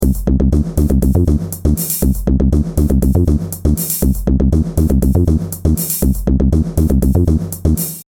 Drum and bass
Sonidos: Música